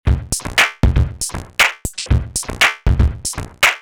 Here’s some direct audio examples of sliced WAV imports. DigiPRO sample playback on the Monomachine becomes way cleaner and more usable when we bypass slot normalisation and keep the original gain structure intact across a loop or single hit.
(1 drum hits, 2 break, 3 303, 4 break)